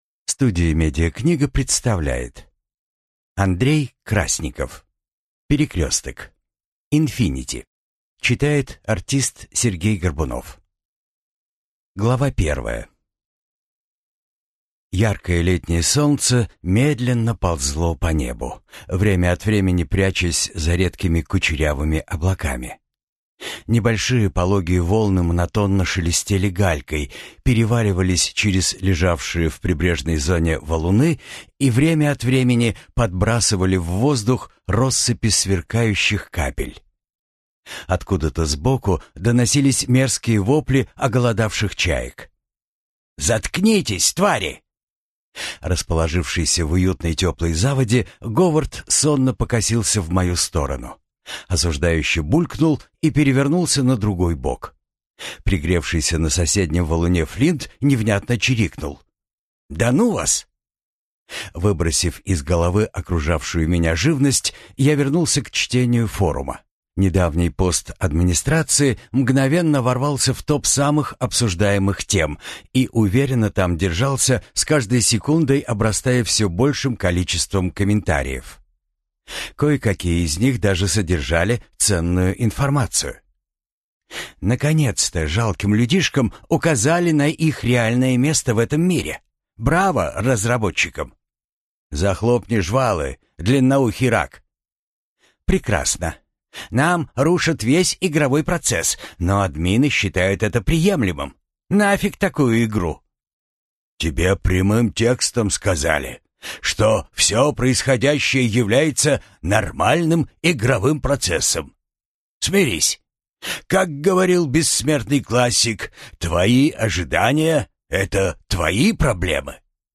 Аудиокнига Перекресток.